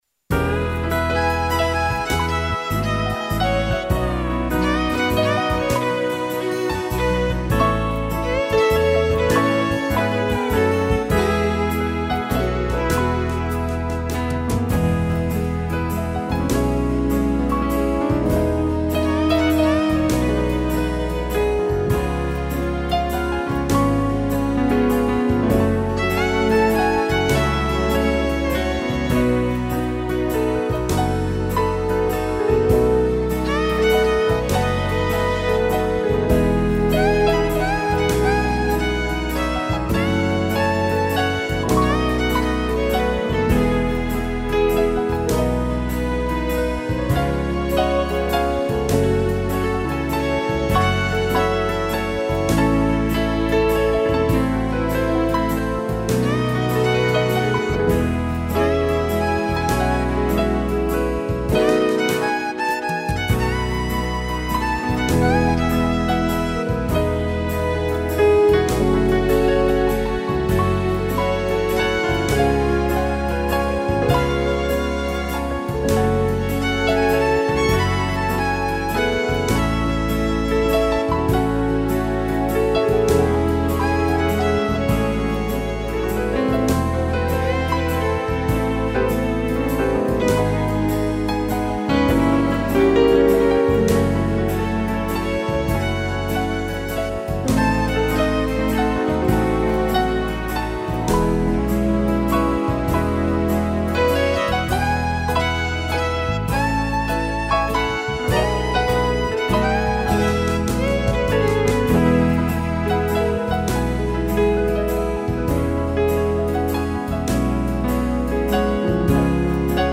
piano e violino